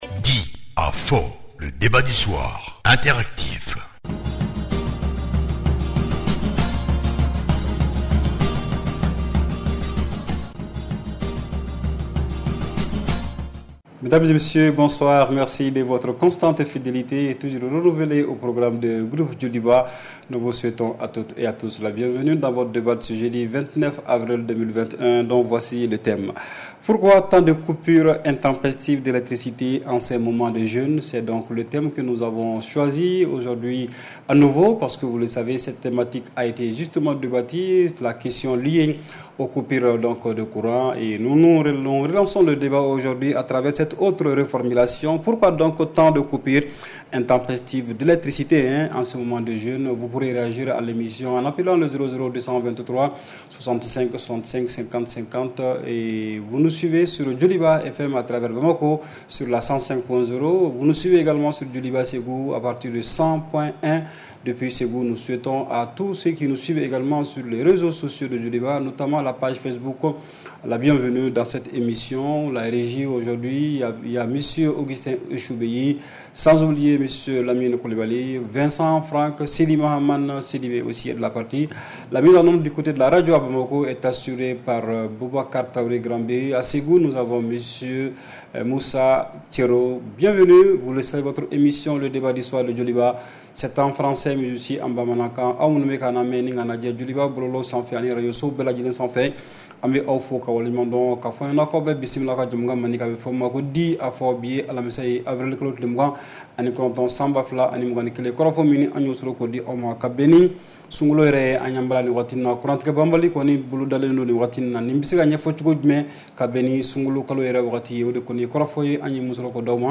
REPLAY 29/04 – « DIS ! » Le Débat Interactif du Soir